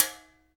PRC GRATER0F.wav